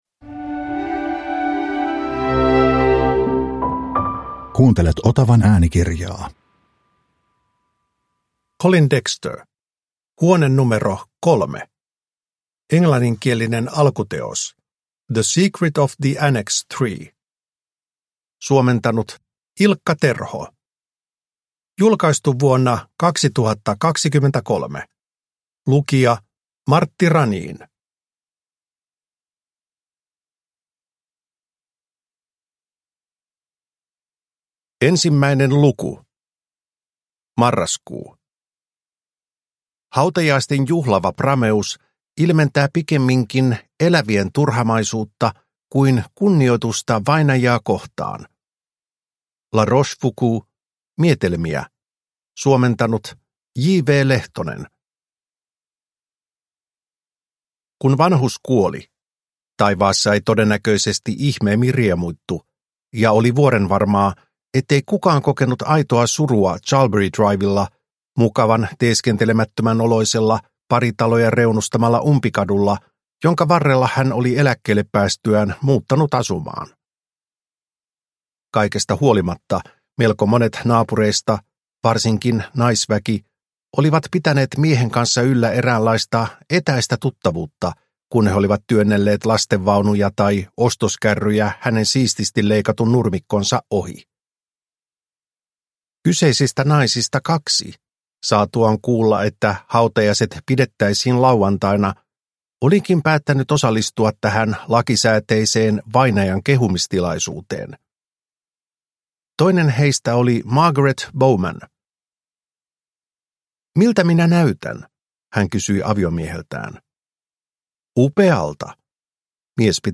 Huone numero 3 – Ljudbok – Laddas ner